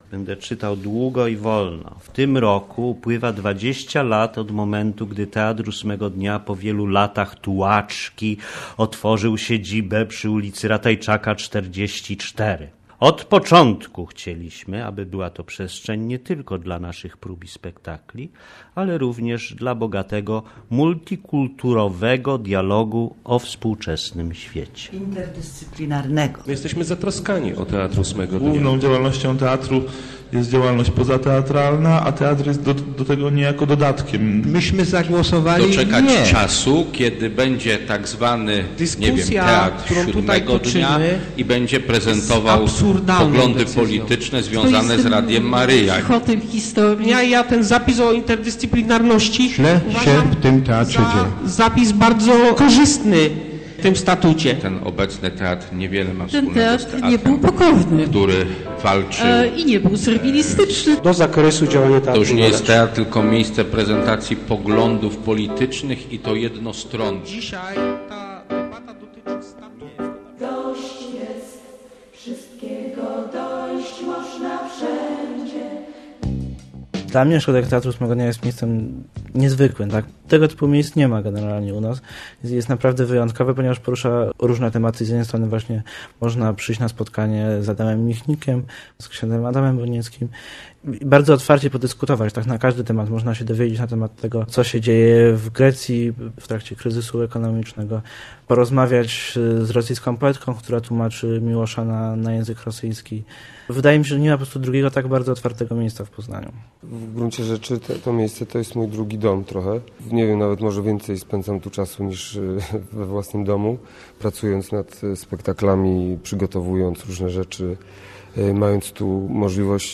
Interdyscyplinarni - reportaż